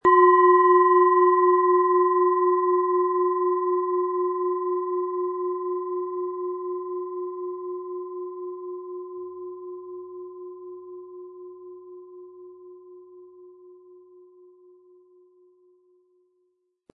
Planetenton
Wasser
Sie möchten den schönen Klang dieser Schale hören? Spielen Sie bitte den Originalklang im Sound-Player - Jetzt reinhören ab.
Besonders schöne Töne zaubern Sie aus der Klangschale, wenn Sie sie sanft mit dem beiliegenden Klöppel anspielen.
SchalenformBihar
MaterialBronze